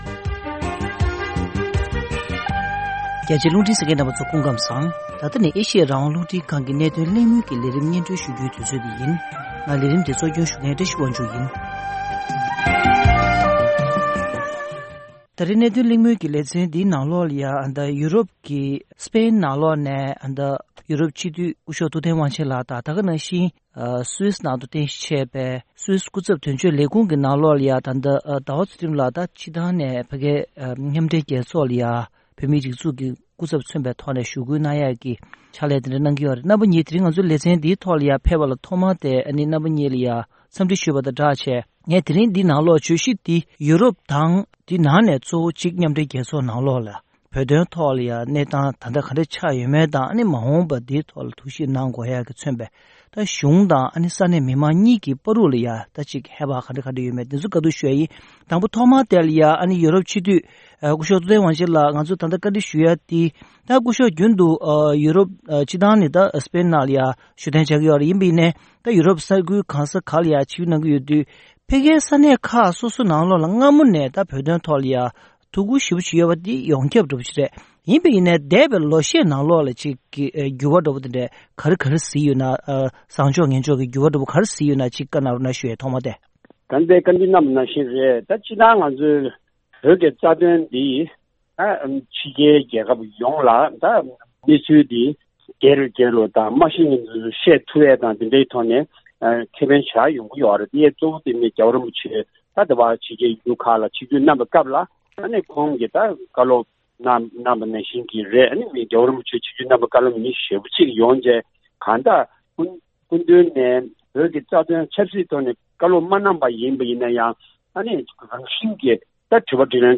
ཡོ་རོབ་སྤྱི་དང་མཉམ་སྦྲེལ་རྒྱལ་ཚོགས་ཀྱི་འགྲོ་བ་་མིའི་ཐོབ་ཐང་ལྷན་ཁང་ཁག་གི་རིམ་པའི་ཁྲོད་བོད་དོན་ལ་དོ་འཁུར་དང་ད་ལྟའི་གནས་བབ་ཐད་གླེང་མོལ།